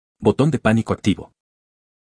Ademas de las ventanas emergentes se recomienda agregar una alerta de sonido para identificar tanto visualmente de manera sonora y dar seguimiento mas oportuno a escenarios criticos.